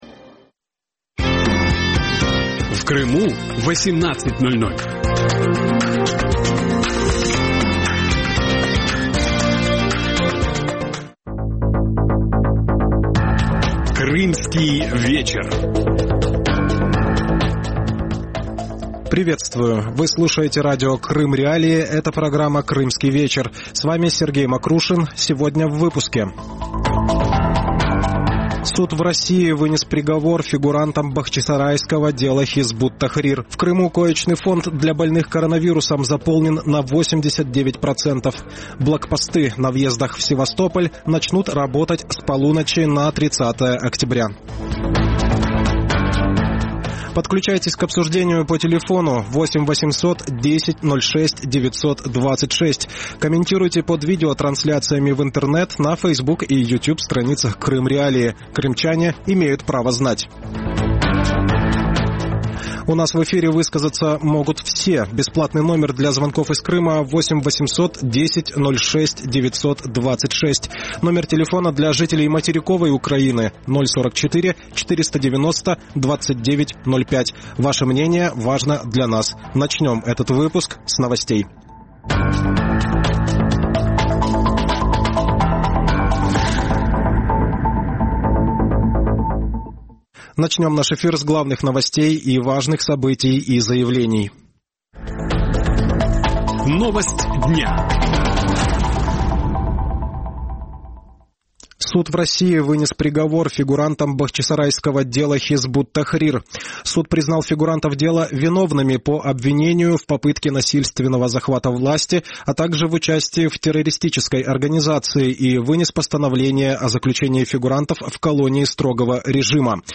Вечерние новости